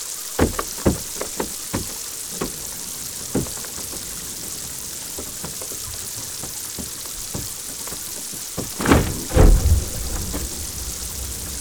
Dessus sont installés quatre micros « hydrophones », spécialisés dans l’enregistrement des sons dans l’eau.
Régulièrement, des pans entiers du front du glacier s’effondrent dans un coup de tonnerre.
Les fracturations d’un iceberg :
Grâce à la plongée effectuée devant le front du glacier, nous avons pu tenter un enregistrement sous-marin en trois dimensions.
Hydrophone_Fracturation_Iceberg.wav